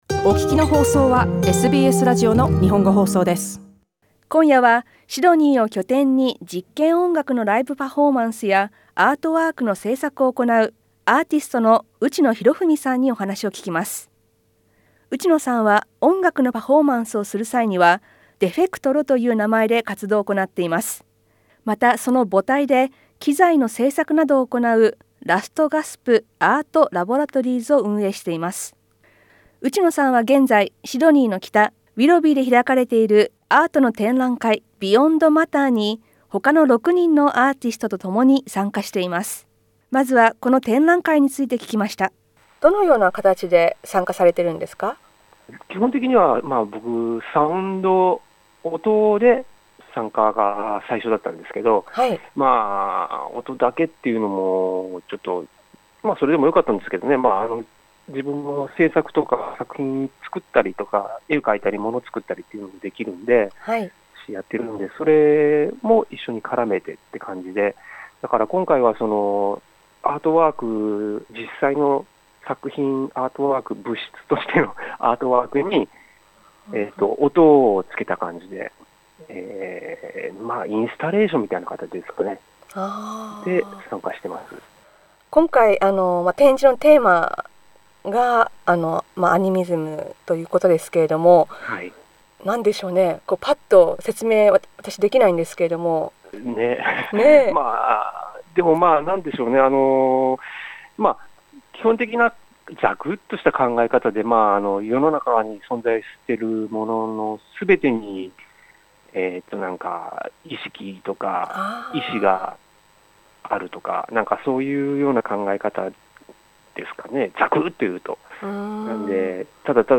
インタビューでは展覧会や制作についてお話を聞きました。